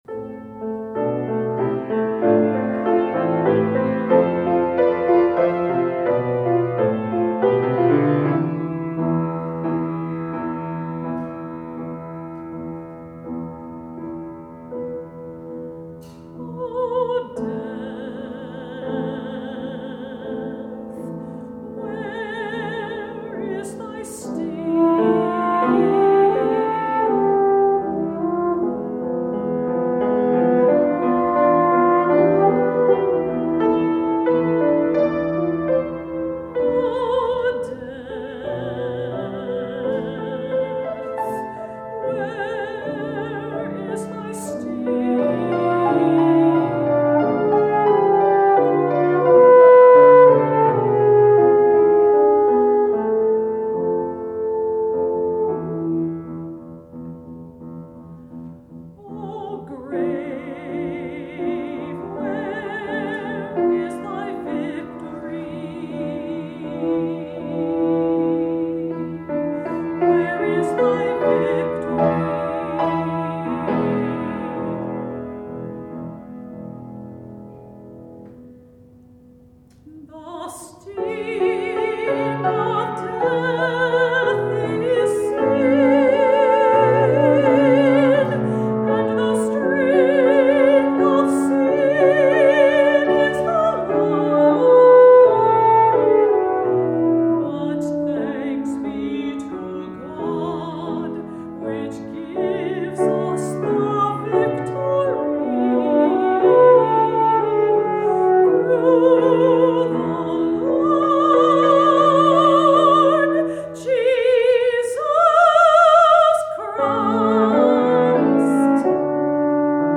Voicing: French Horn w/v